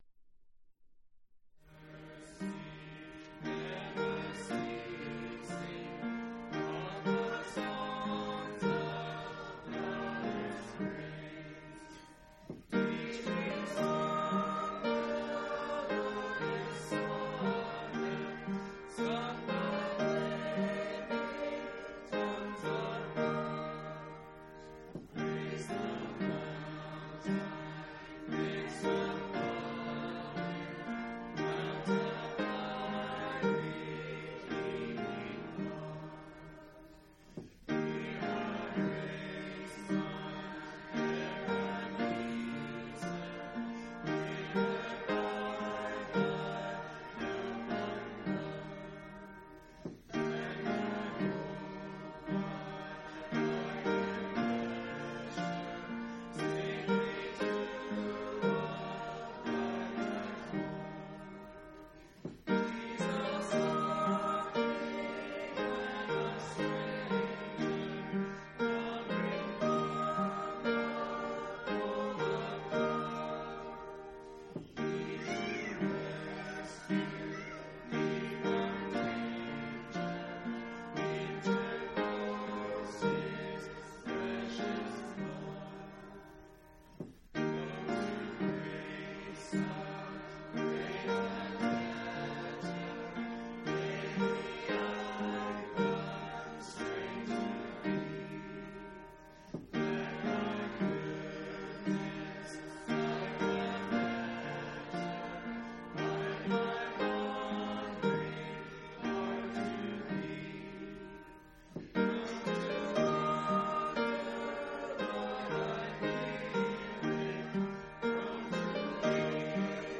2/16/2003 Location: Phoenix Local Event